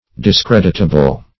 discreditable \dis*cred"it*a*ble\, a.